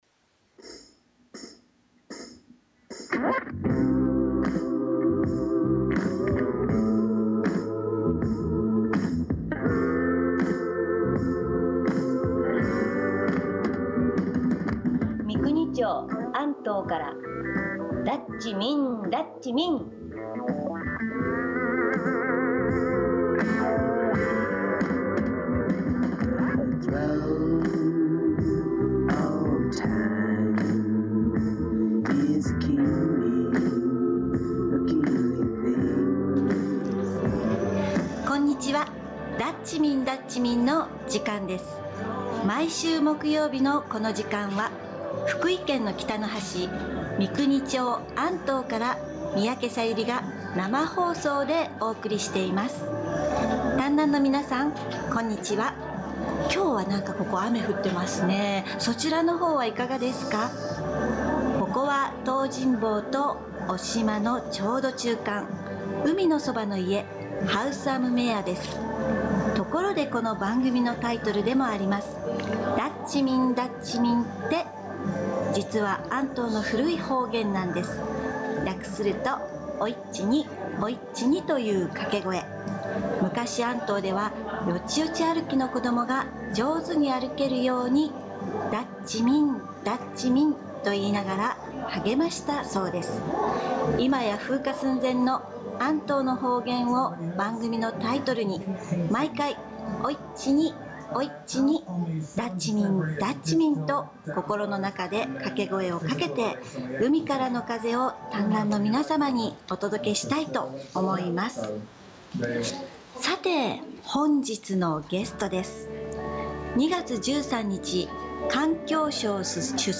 寸劇（約２分） ありがとうございました！